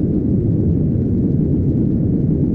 techage_gasflare.ogg